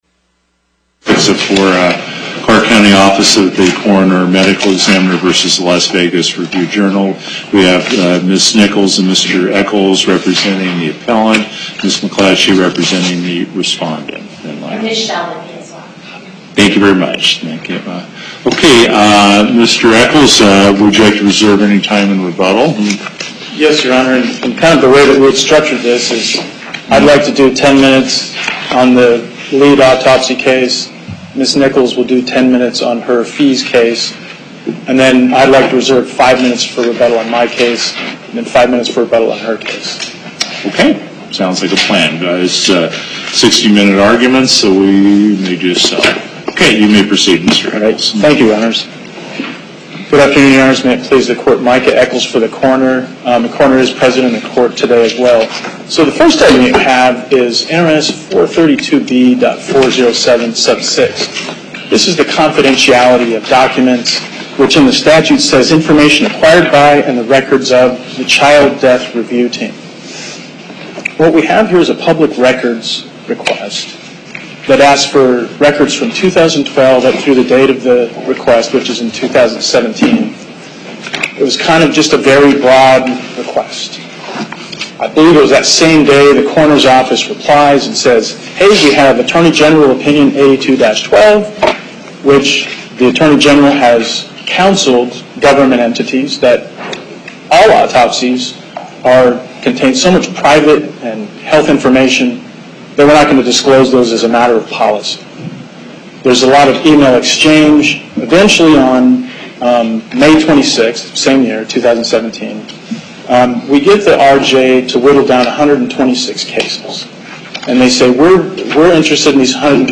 Location: Las Vegas Before the En Banc Court, Chief Justice Gibbons Presiding